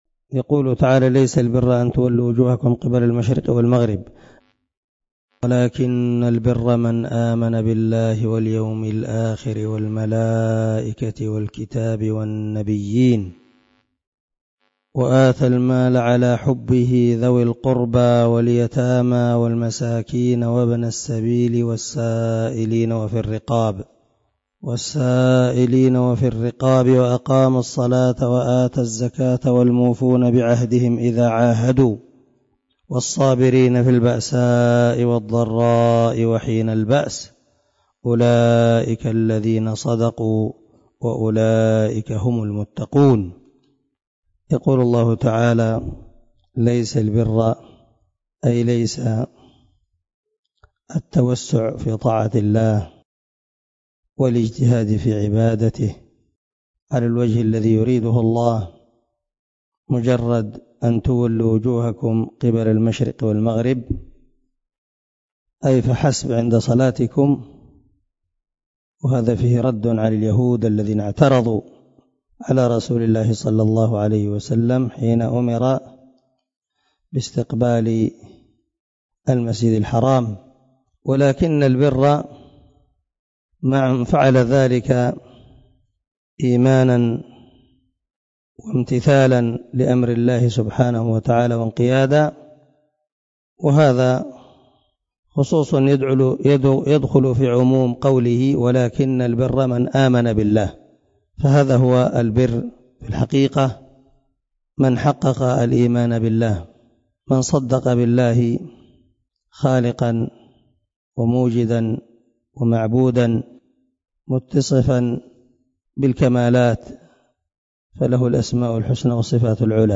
076الدرس 66 تفسير آية ( 177 ) من سورة البقرة من تفسير القران الكريم مع قراءة لتفسير السعدي
دار الحديث- المَحاوِلة- الصبيحة.